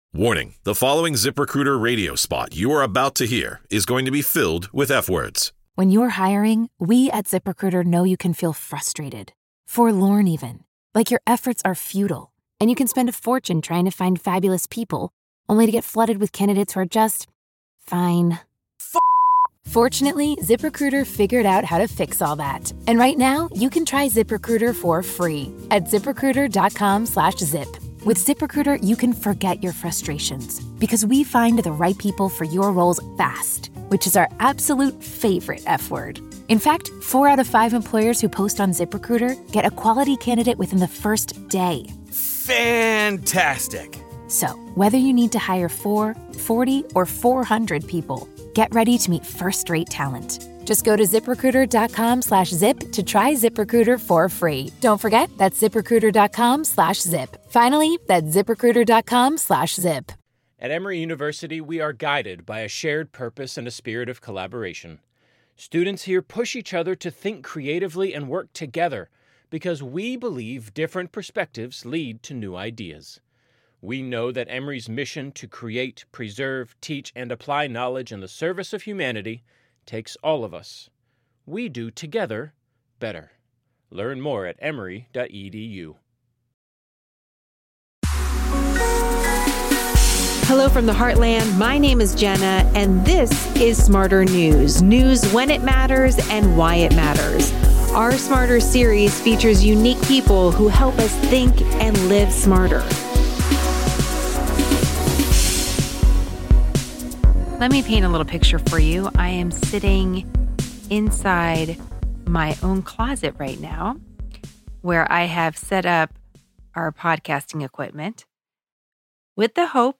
I am sitting inside my own closet right now, where I have set up our podcasting equipment